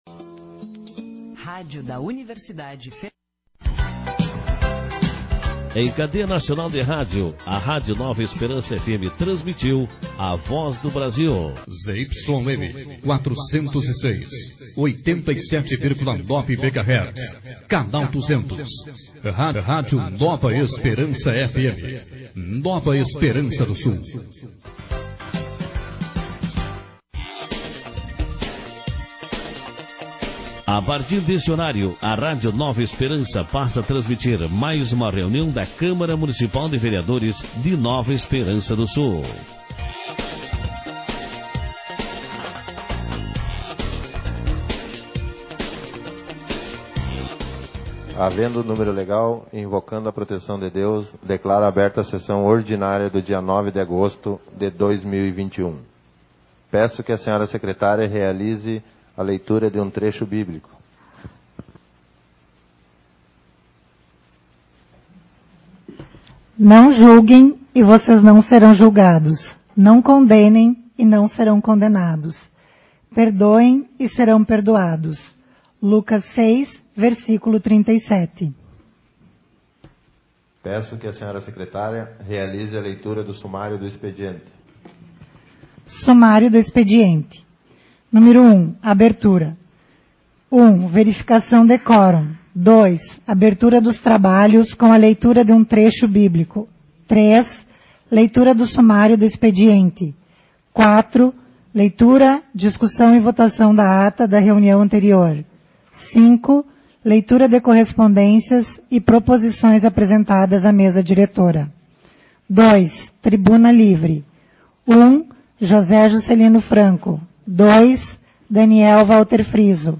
audios sessão — Câmara Municipal de Vereadores